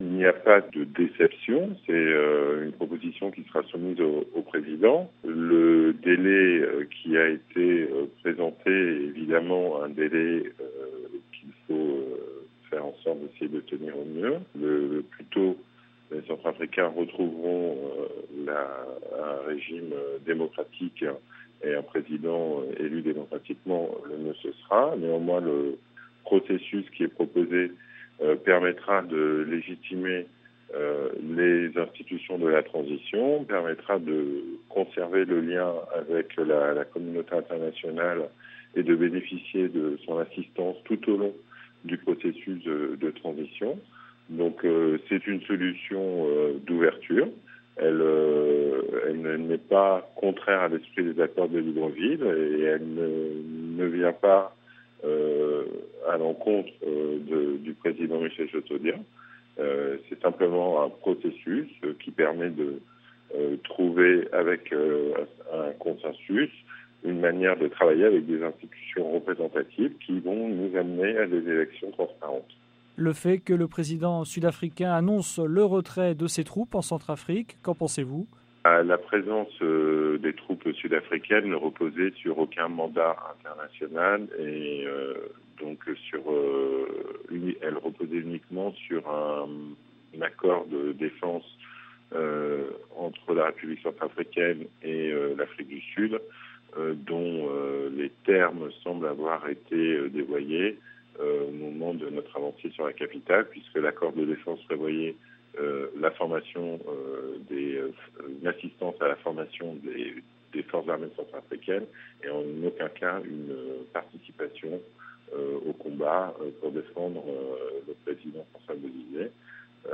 joint à N'Djamena